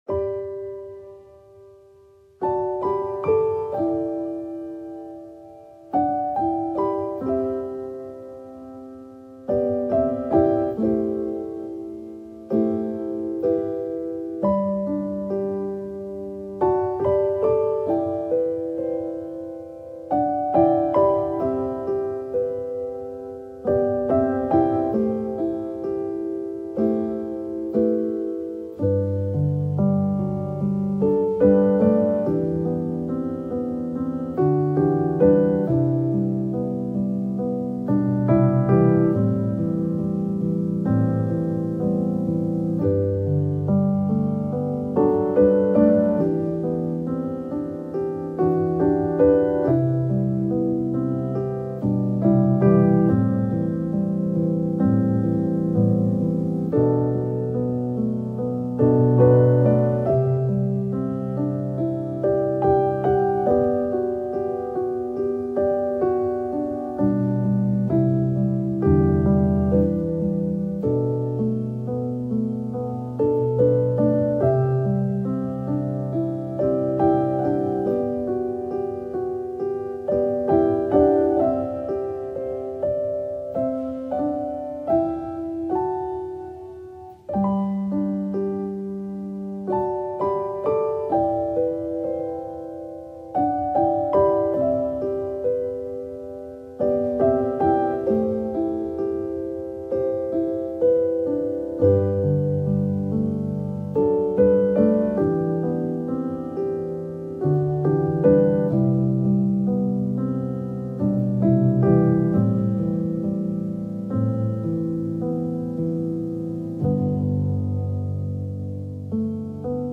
آرامش بخش
پیانو , عصر جدید